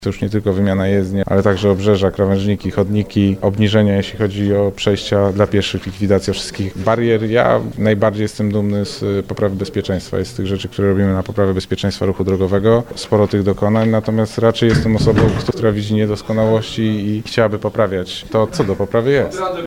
Na konferencji prasowej przedstawiciele ZDM i miasta podsumowali ostatnie dwanaście miesięcy prac remontowych na terenie całej Warszawy.
Prezez ZDM – Łukasz Puchalski podkreśla, że w tym roku zarząd skupił się nie tylko na remontach samych ulic, ale także na zmianach w infrastrukturze wzdłuż jezdni.